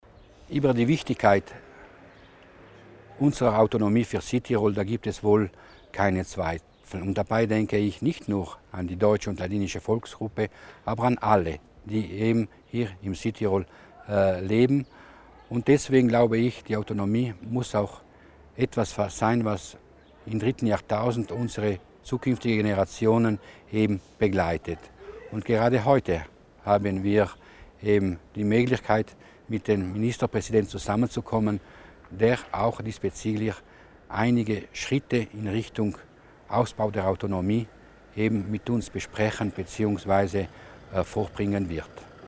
Landesrat Mussner beim Mediengespräch in St. Ulrich.